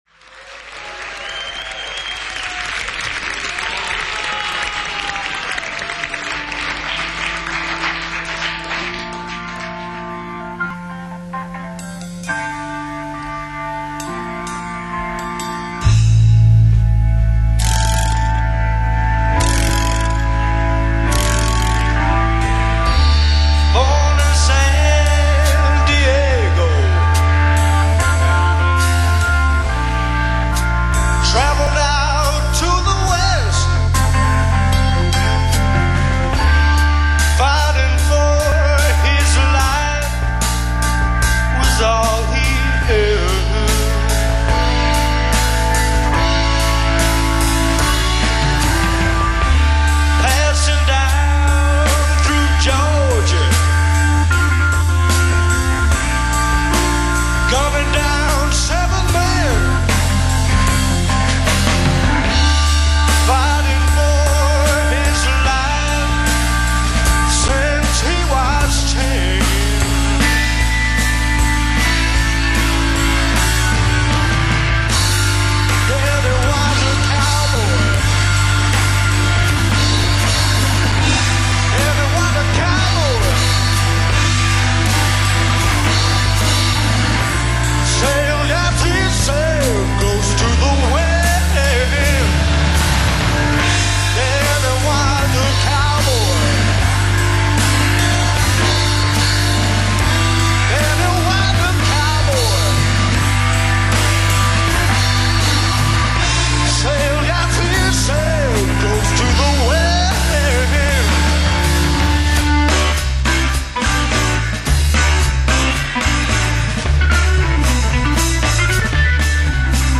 keyboards
percussion
astonishing what only two instruments could do.